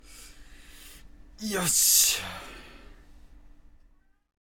やはり、一つより二つですね。